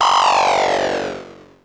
VCS_BELL_5.wav